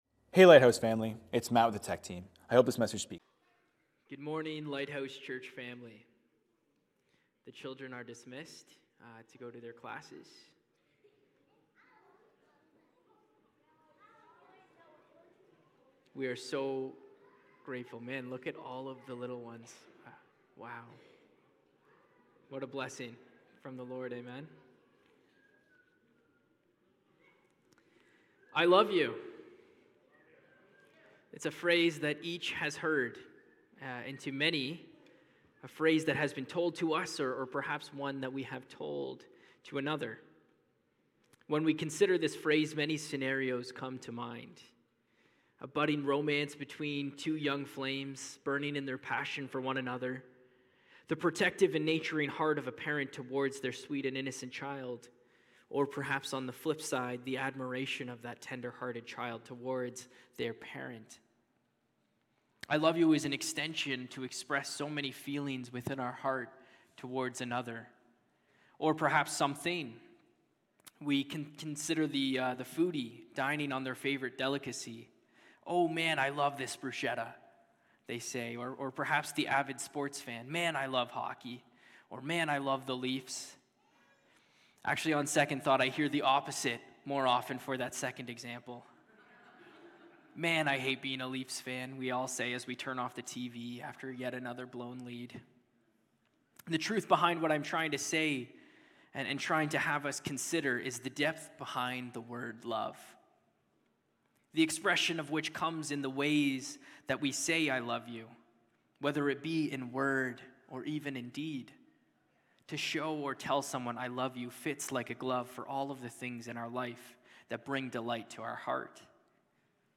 Sunday Morning Service
Lighthouse Niagara Sermons